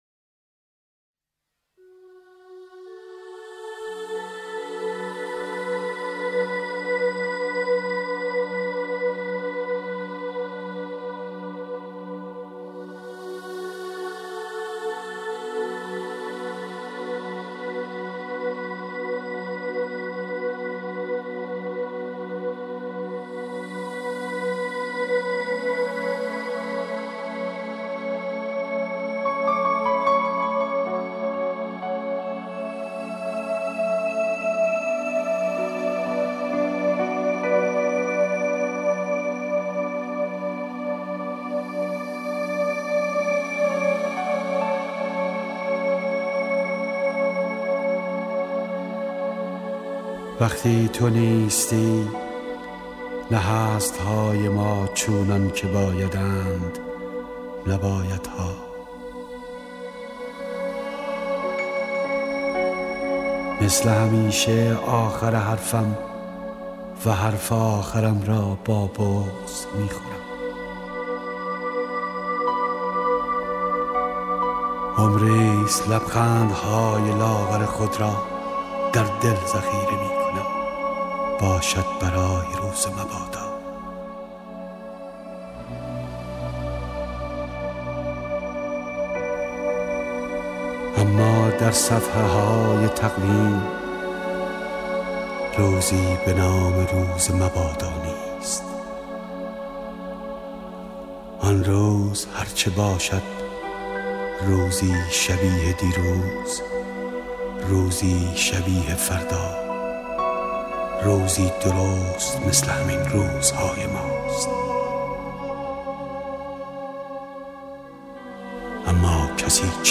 شعر روز مبادا قیصر امین پور را با صدای زنده‌یاد احمدرضا احمدی بشنوید.